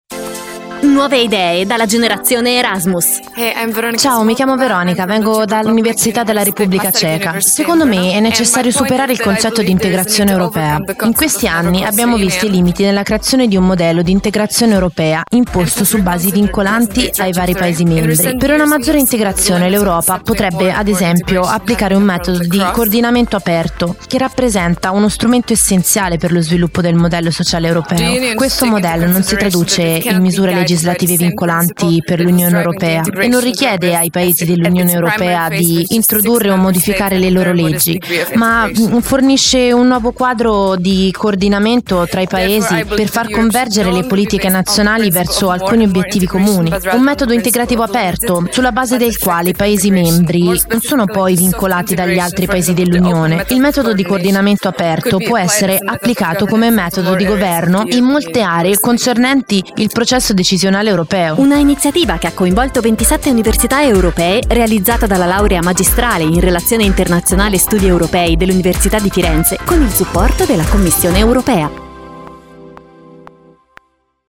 Controradio also realized a series of short interviews with the students who participated to the event.